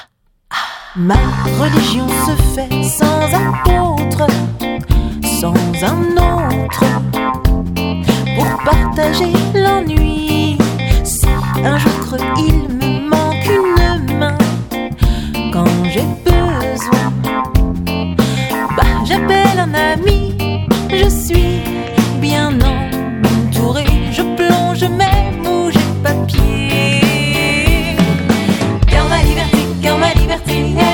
0 => "Chanson francophone"